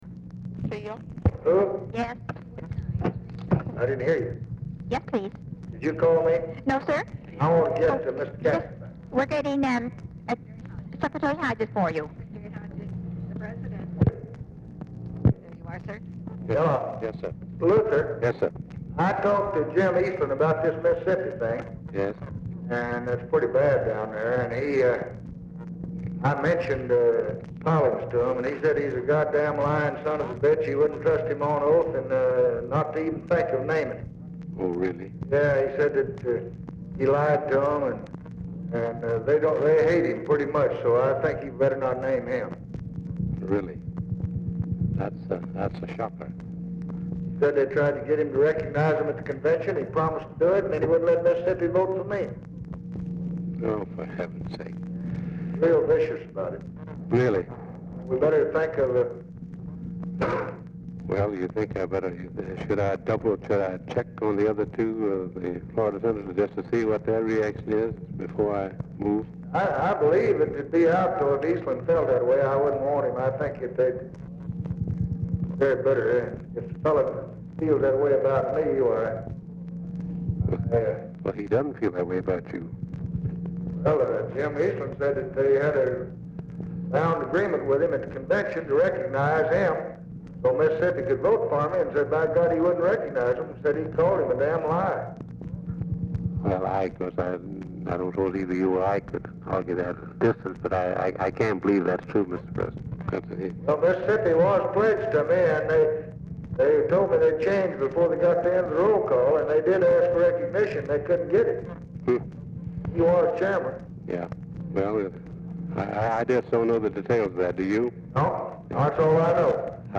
Telephone conversation # 3839, sound recording, LBJ and LUTHER HODGES, 6/23/1964, 4:14PM | Discover LBJ
Format Dictation belt
Location Of Speaker 1 Oval Office or unknown location